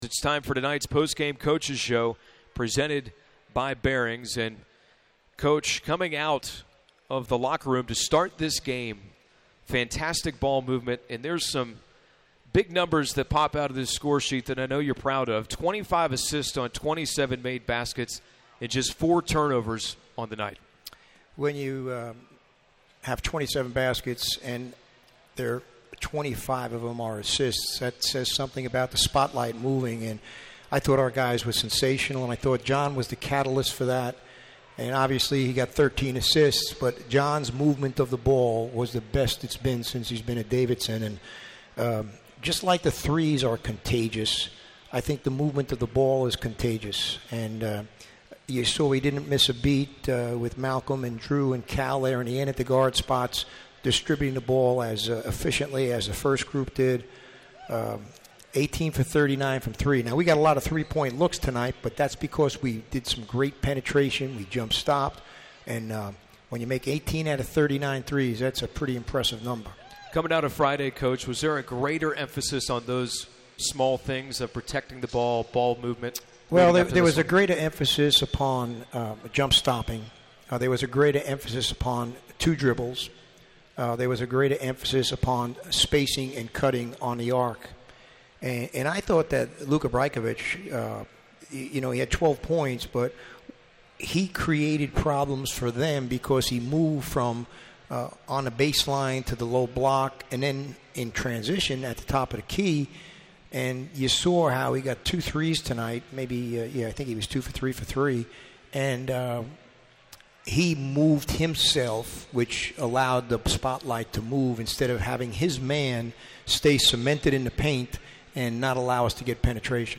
McKillop Postgame Interview